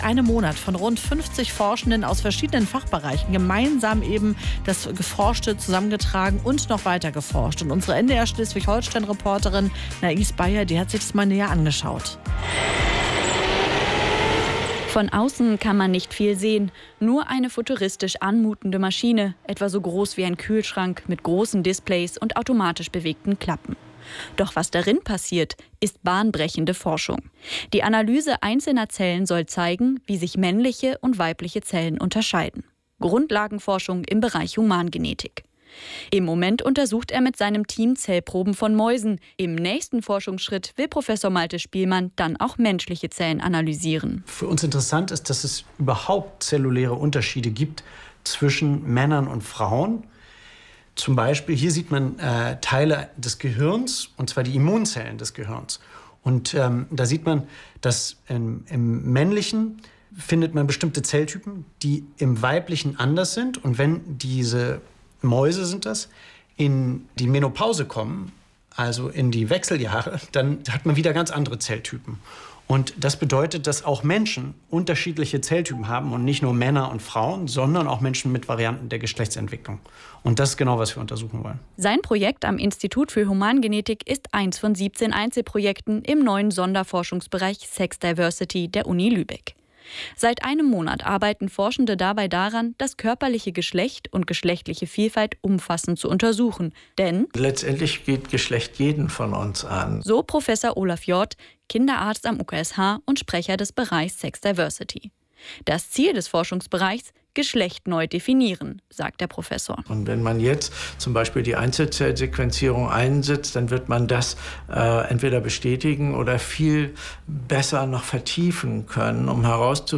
Im MDR-Live-Talk zum Selbstbestimmungsgesetz vom 23.